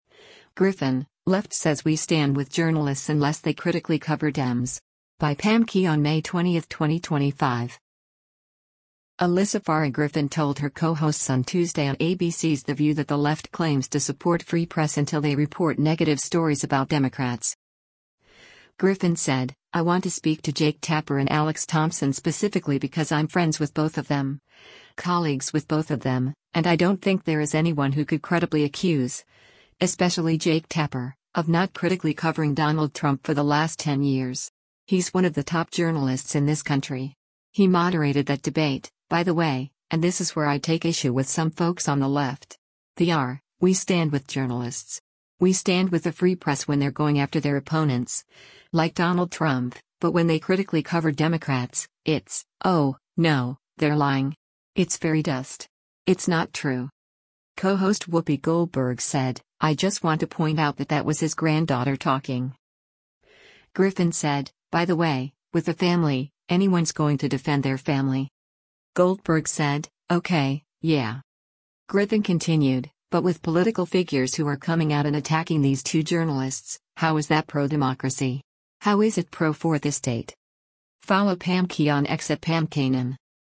Alyssa Farah Griffin told her co-hosts on Tuesday on ABC’s “The View” that the left claims to support free press until they report negative stories about Democrats.